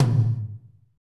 Index of /90_sSampleCDs/Northstar - Drumscapes Roland/DRM_Techno Rock/TOM_F_T Toms x